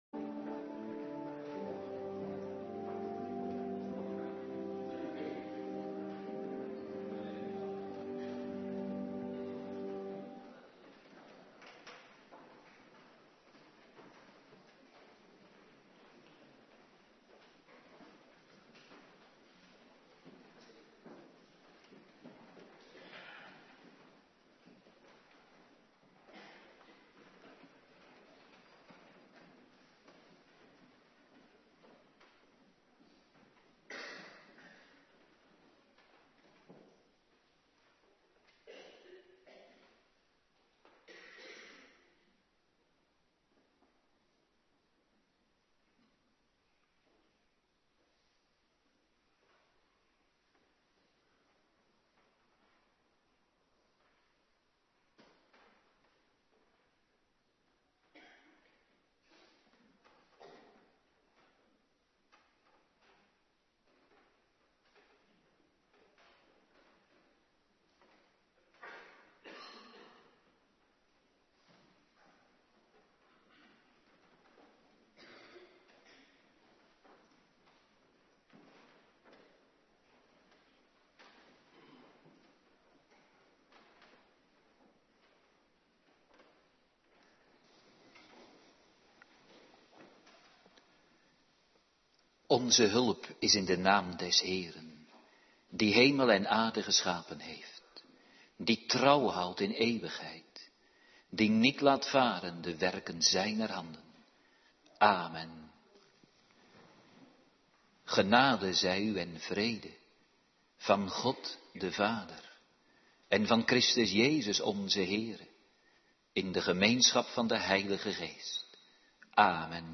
Avonddienst
18:30 t/m 20:00 Locatie: Hervormde Gemeente Waarder Agenda